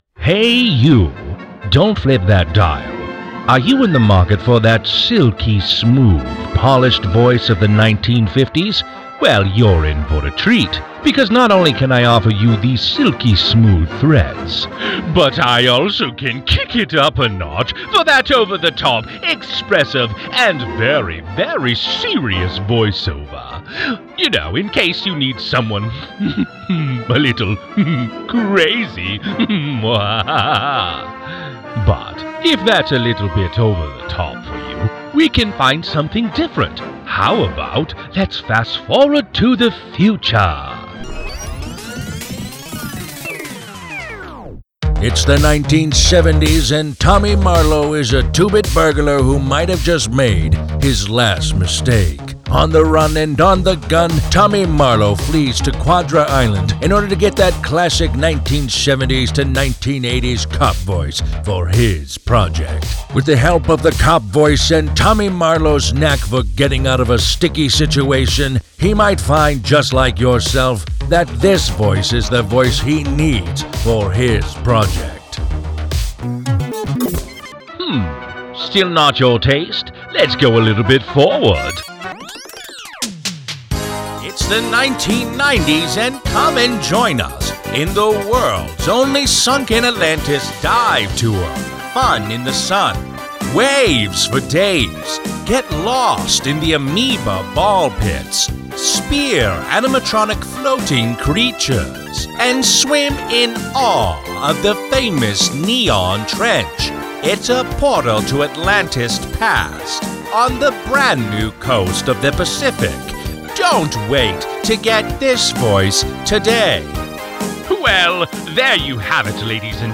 Live Announcer
1950s 70s 90s Voice Over Male